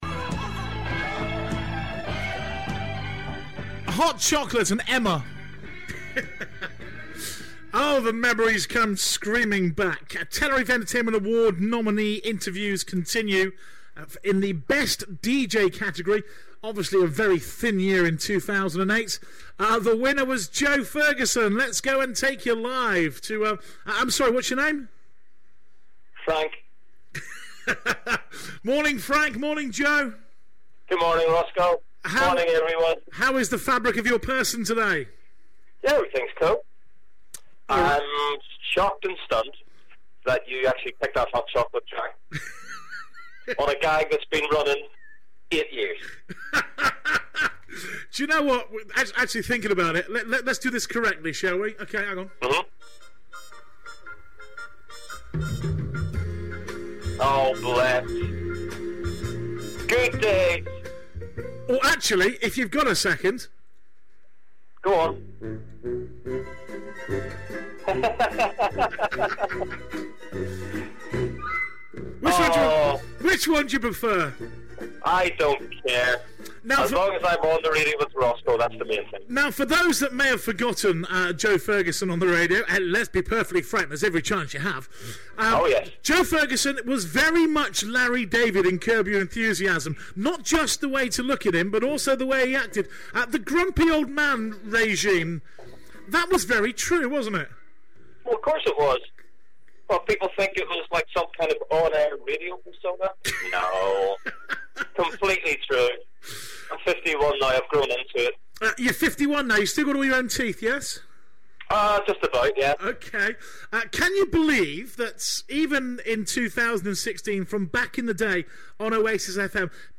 breakfast show